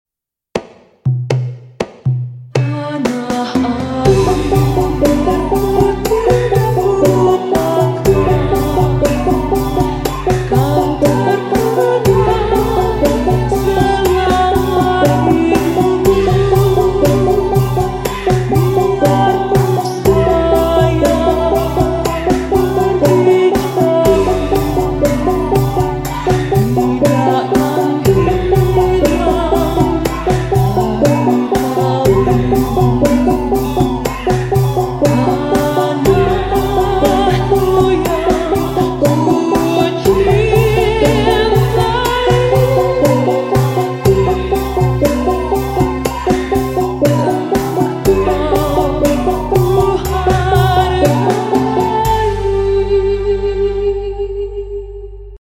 Mahkota Live Musik , Prima Sound Effects Free Download